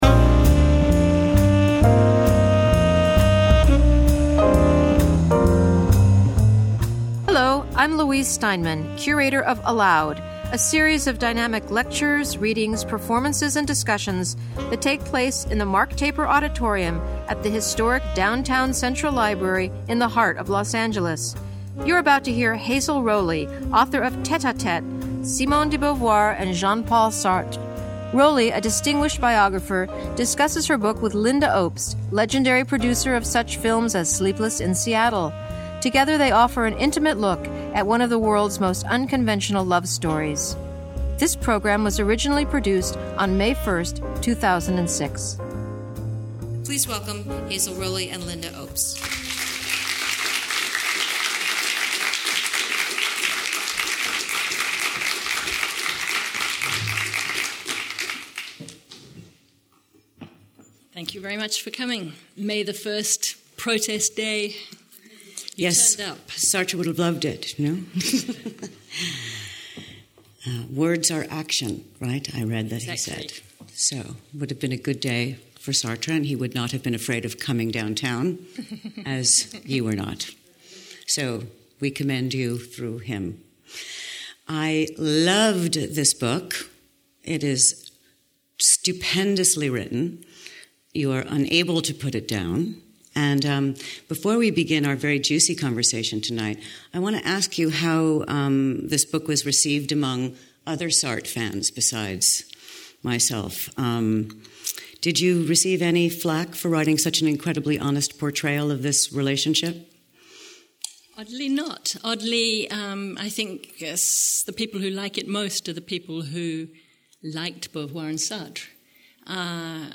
In conversation with Lynda Obst